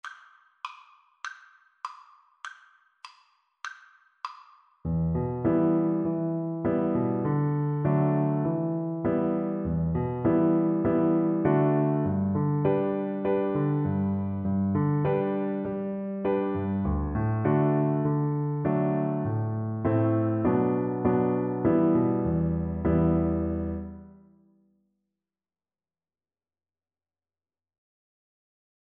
E minor (Sounding Pitch) (View more E minor Music for Cello )
2/4 (View more 2/4 Music)
Moderato